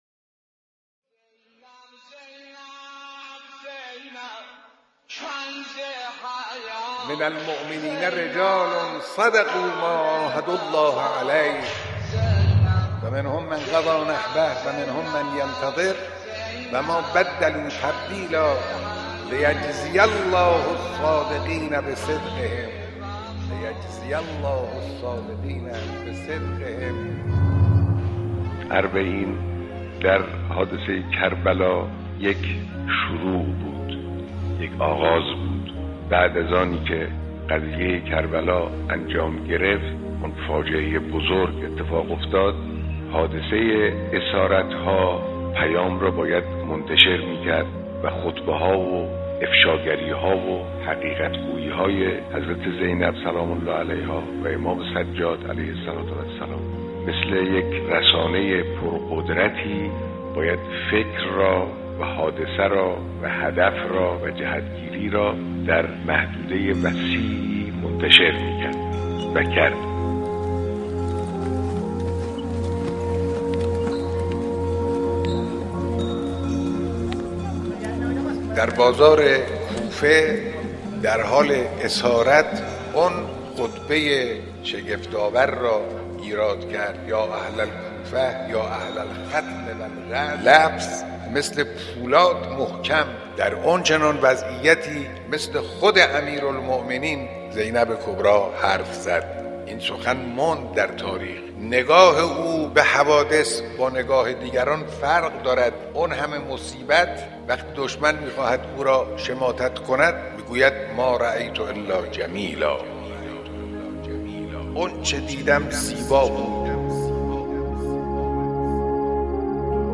صوت/سخنرانی رهبر معظم انقلاب پیرامون شخصیت حضرت زینب (س)